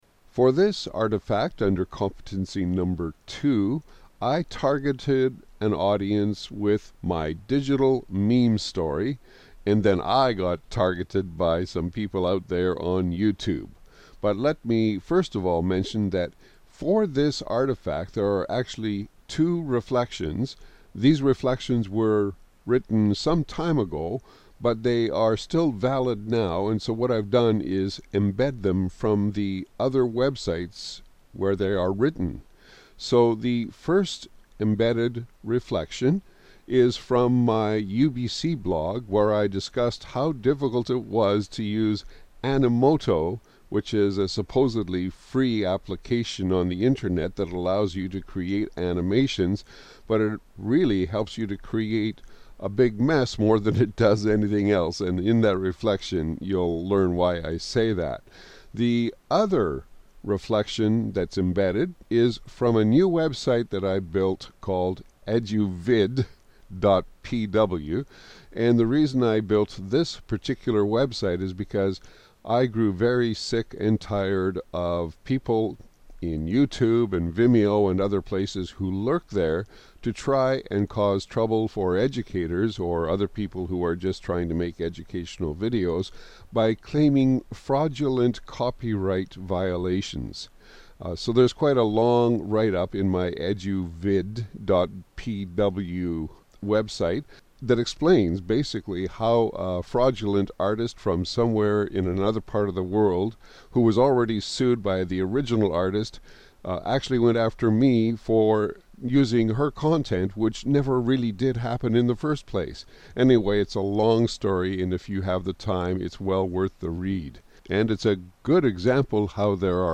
digitalstoryreflection.mp3